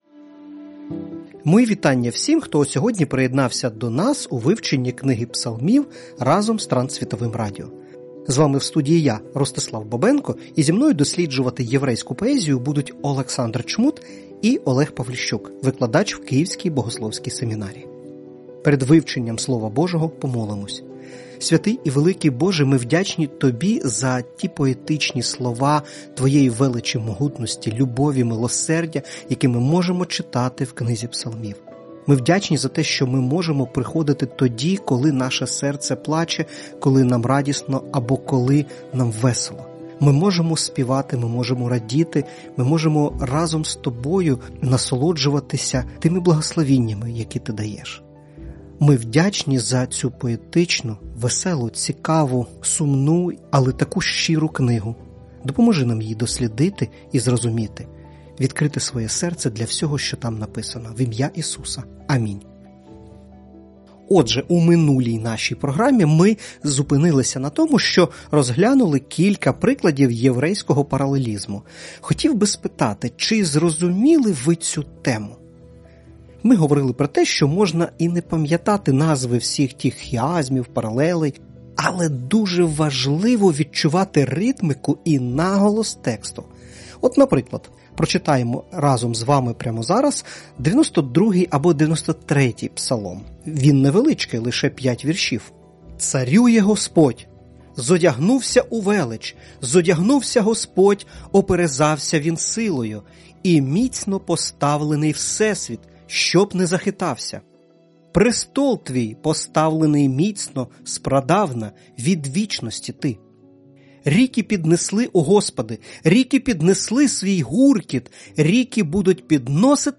Сьогодні будемо говорити з вами про те що Псалми переповнені спогляданням величі всесвіту в контексті зображення Творця. Щоб побачити дивні образи і порівняння звернемо увагу на дев’яносто третій псалом. А потім заспіваємо пісню про пастиря.